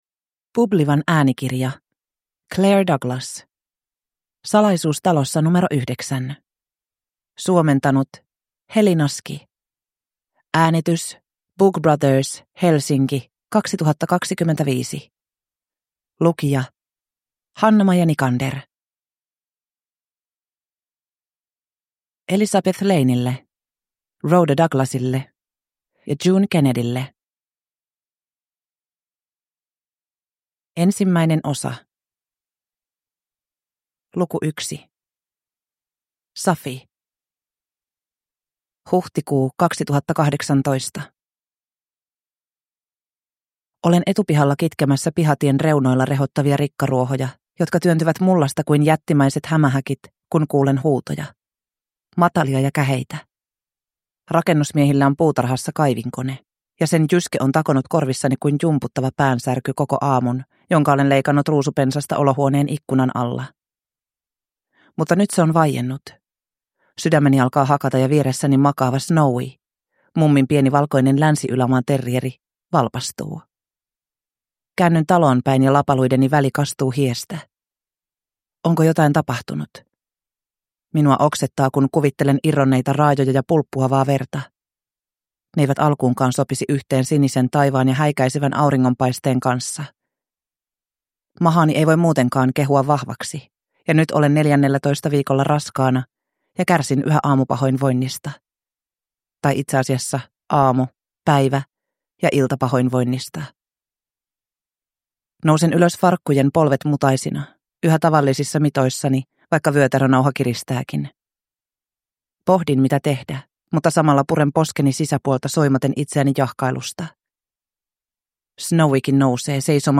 Salaisuus talossa nro 9 (ljudbok) av Claire Douglas